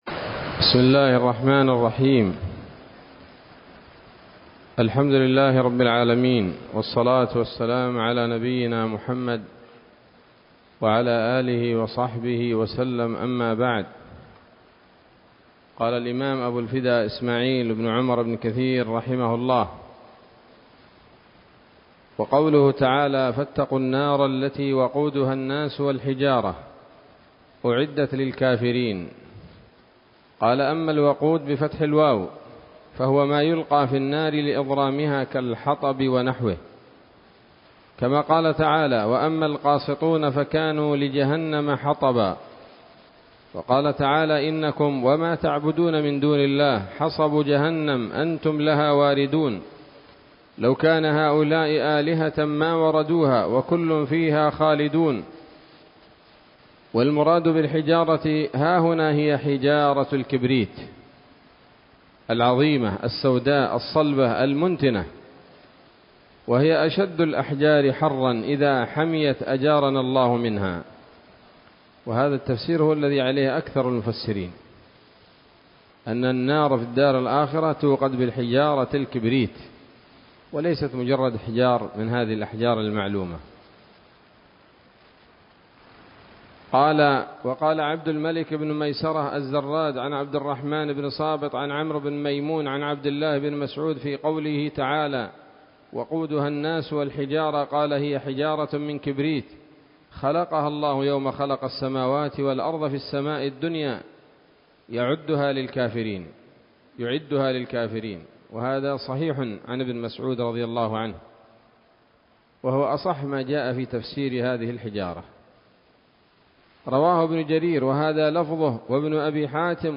الدرس السادس والعشرون من سورة البقرة من تفسير ابن كثير رحمه الله تعالى